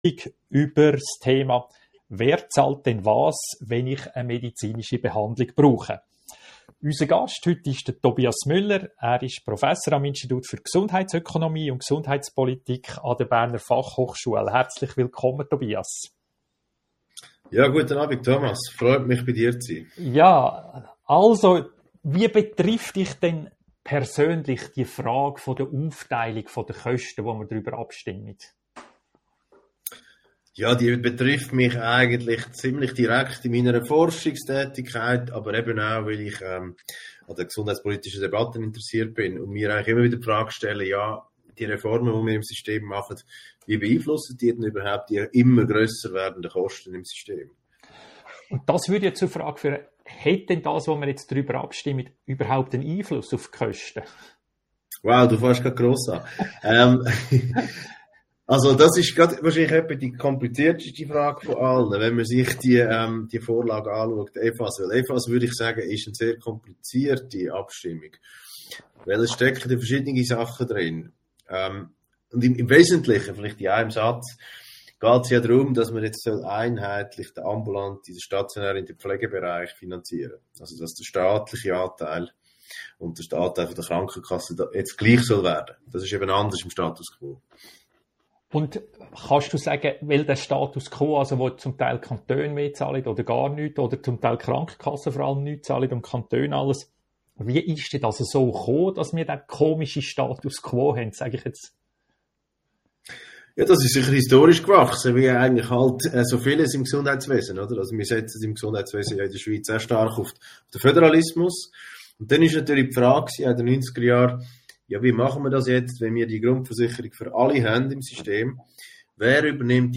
Radio🎙einFluss Podcasts hören Bleiben Sie über die kommenden Radio🎙einFluss Audio-Gespräche informiert!